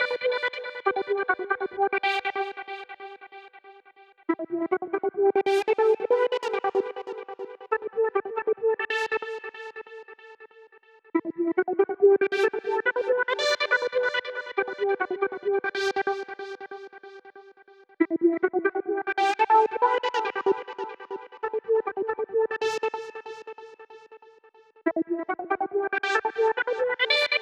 VTS1 Incast Kit 140BPM Atmosynth.wav